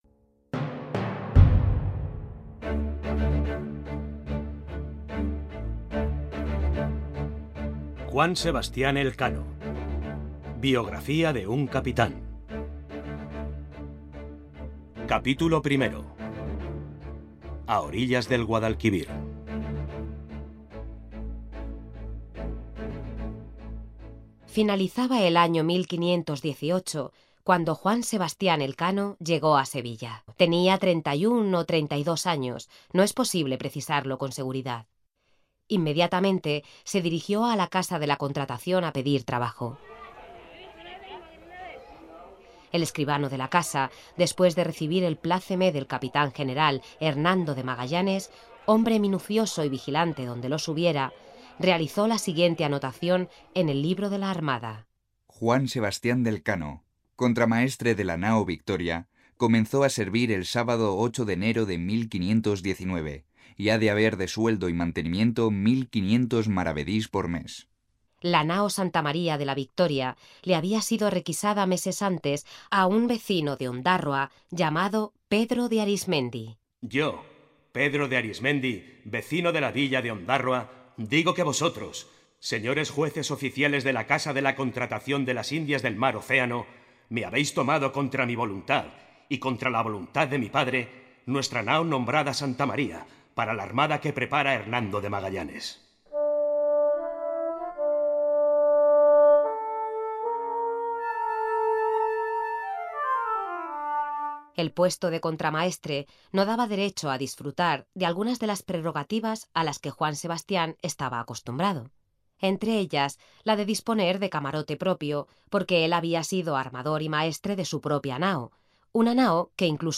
Audiolibro: Elkano biografía de un cápitan capítulo 1